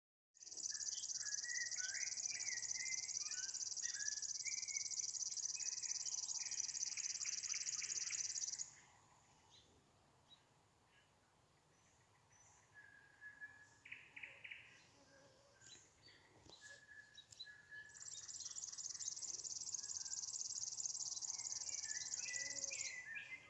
Birds -> Warblers ->
River Warbler, Locustella fluviatilis
StatusSinging male in breeding season